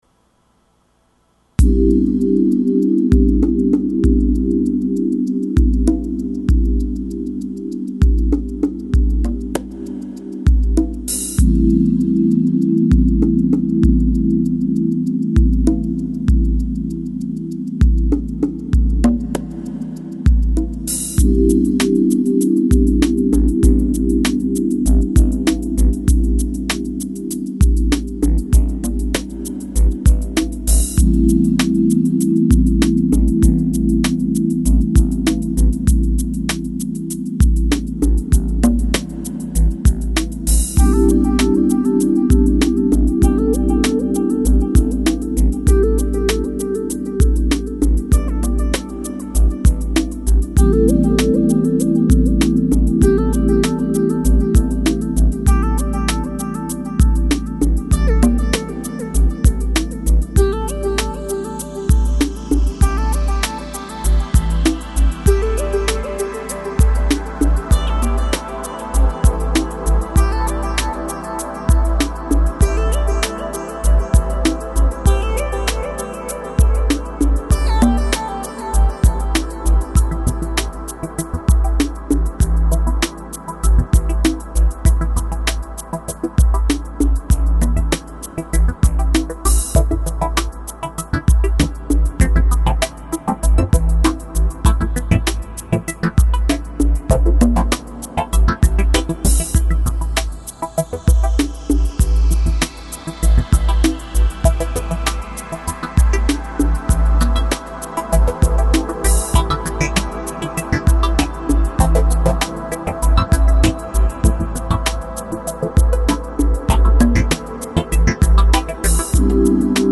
Жанр: Balearic, Downtempo